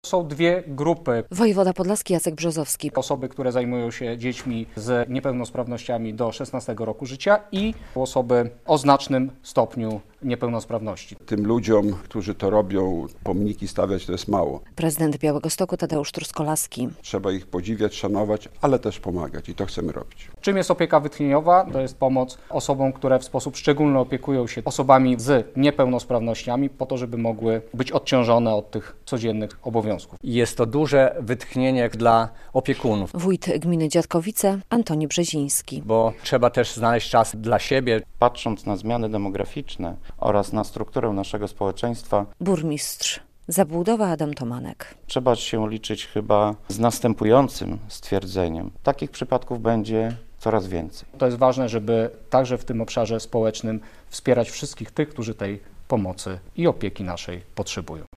Podlascy samorządowcy podpisali umowy na realizację programu Opieka Wytchnieniowa - relacja
Ten program skierowany jest do dwóch grup - mówi wojewoda podlaski Jacek Brzozowski.